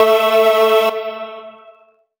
54_26_organ-A.wav